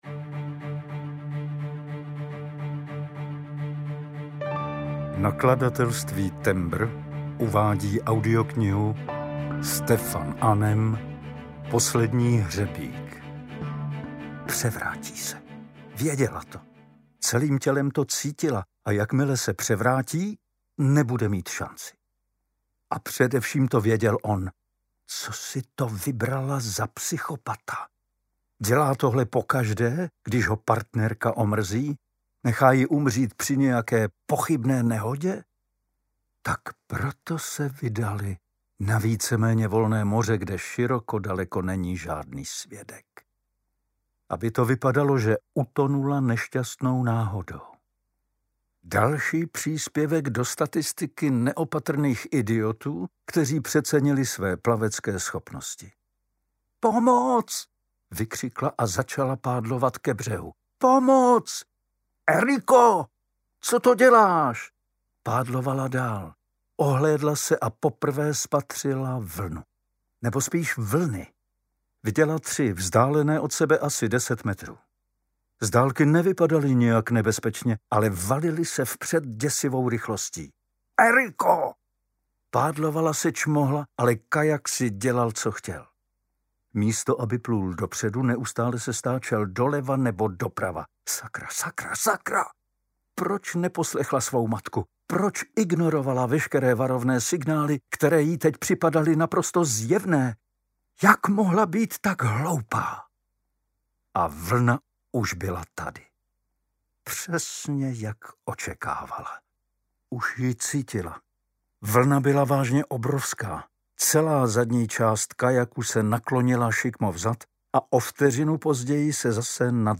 Poslední hřebík audiokniha
Ukázka z knihy